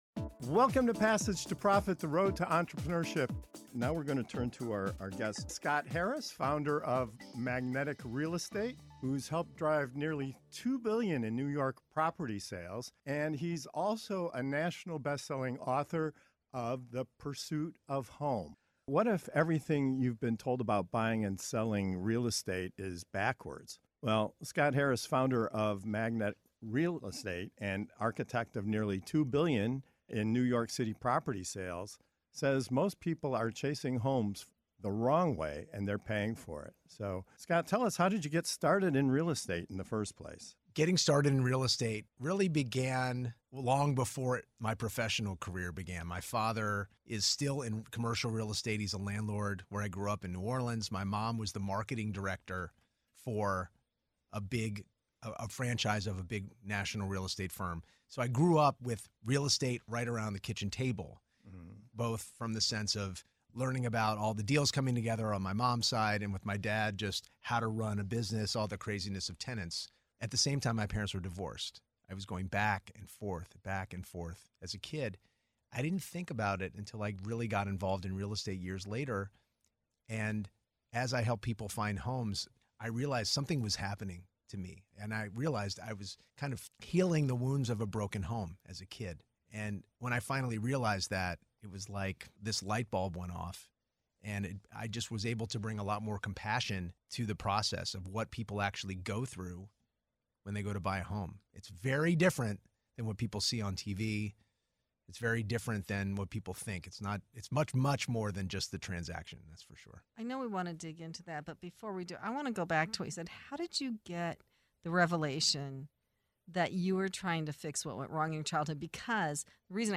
co-hosts of the Passage to Profit Show, interview